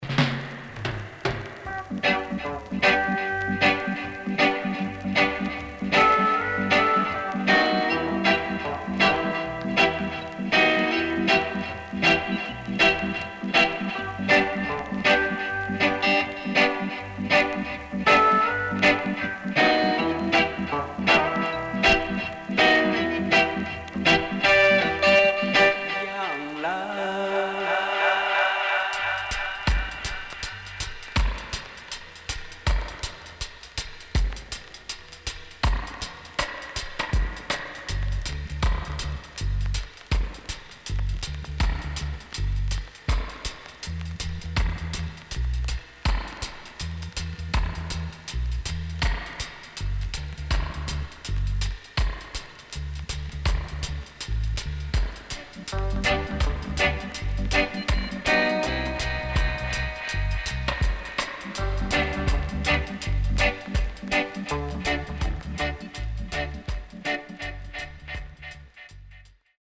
HOME > Back Order [VINTAGE 7inch]
CONDITION SIDE A:VG(OK)
SIDE A:全体的にかるいヒスノイズ入ります。